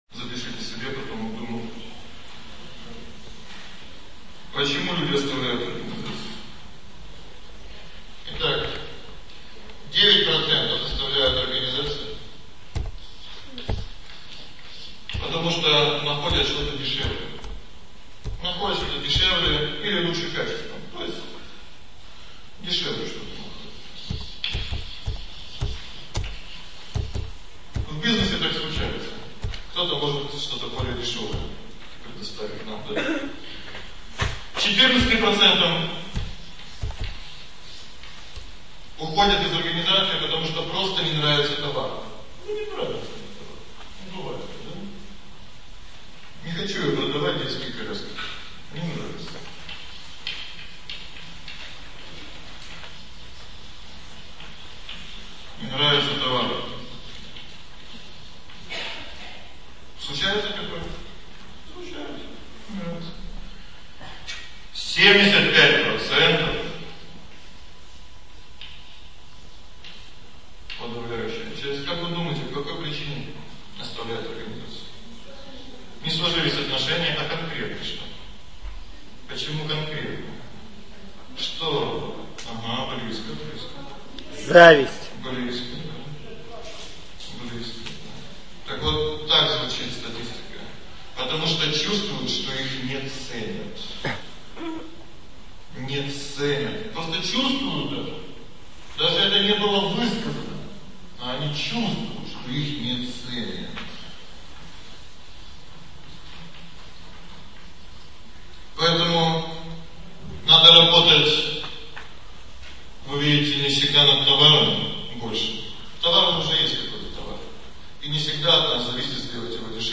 Аудиокнига: Ведическая конфликтология